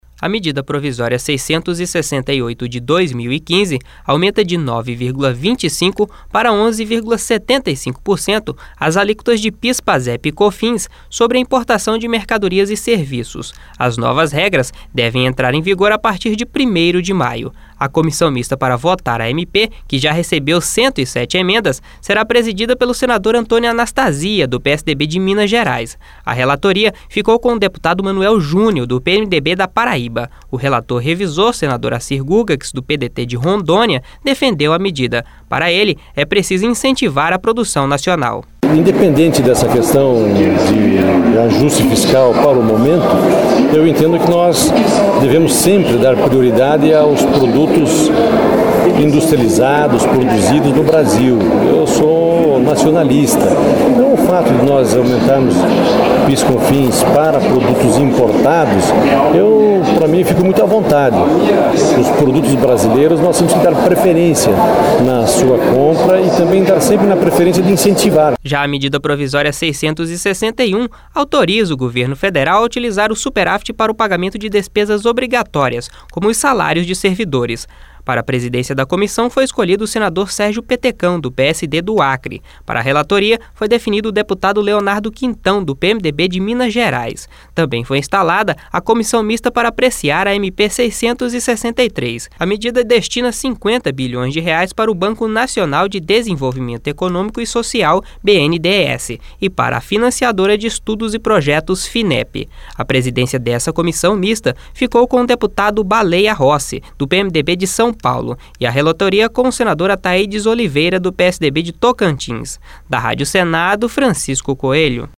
O relator revisor, senador Acir Gurgacz, do PDT de Rondônia, defendeu a medida.